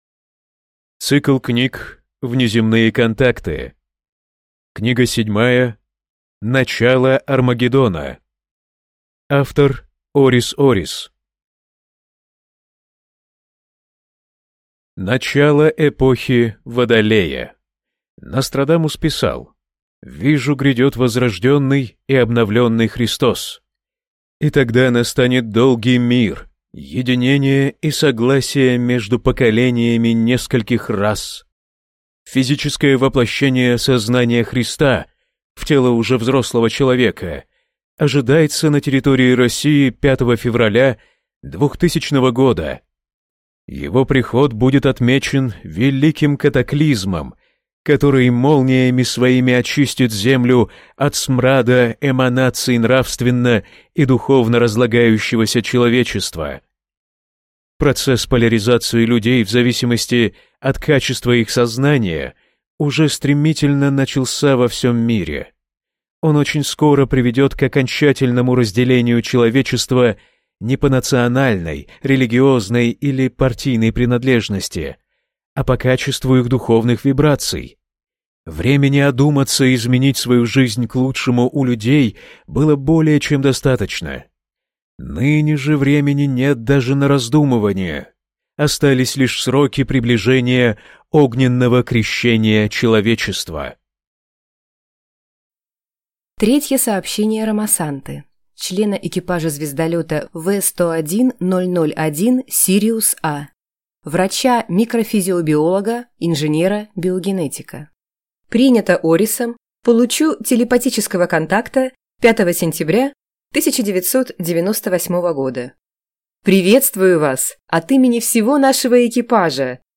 Аудиокнига Начало Армагеддона | Библиотека аудиокниг